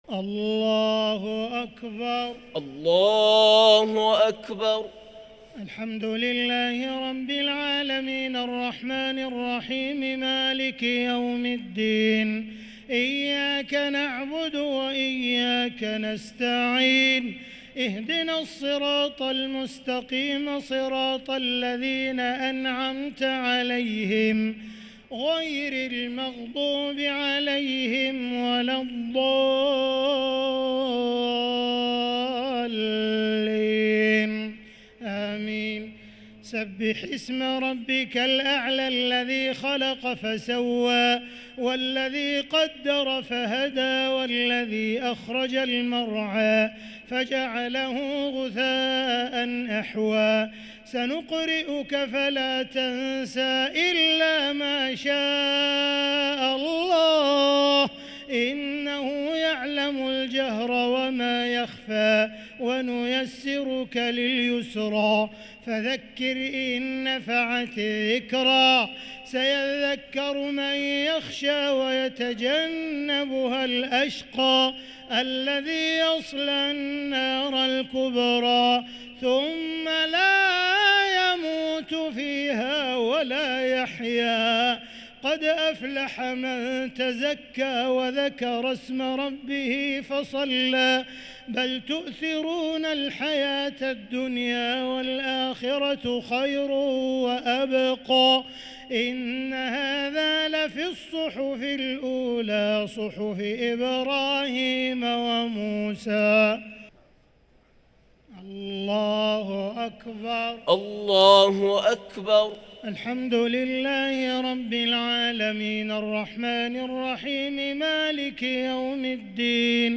الشفع والوتر ليلة 19 رمضان 1444هـ | Witr 19 st night Ramadan 1444H > تراويح الحرم المكي عام 1444 🕋 > التراويح - تلاوات الحرمين